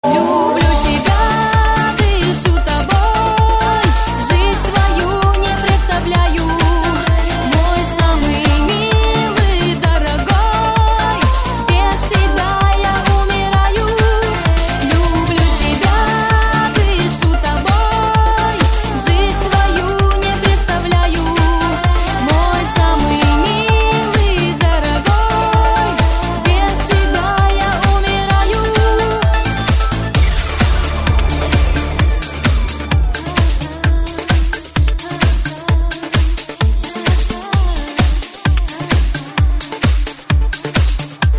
женский вокал